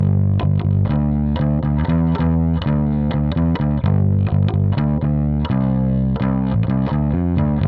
Tag: 125 bpm Rock Loops Bass Loops 661.53 KB wav Key : A